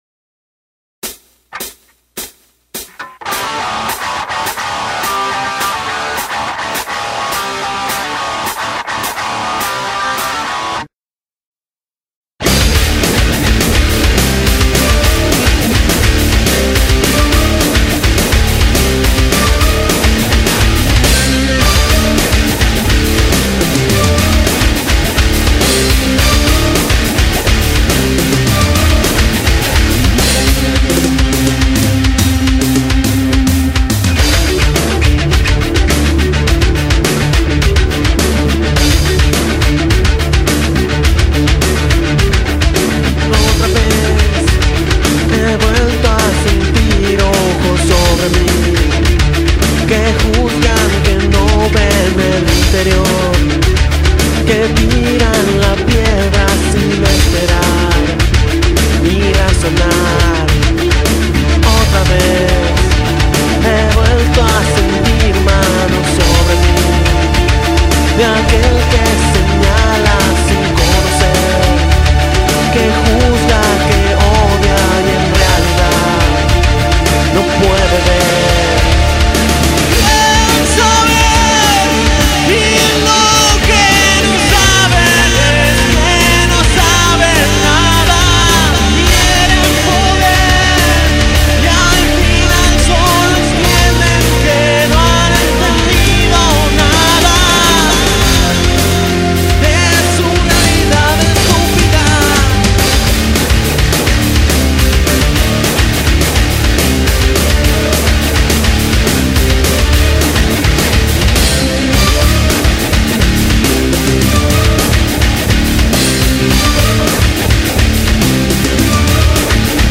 Rock Alternativo